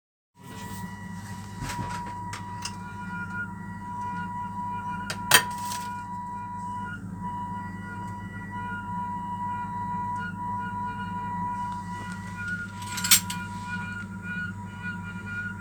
После запуска, практически сразу, котел начинает издавать скрежещуший звук, который длится до 2 минут. Потом постепенно исчезает.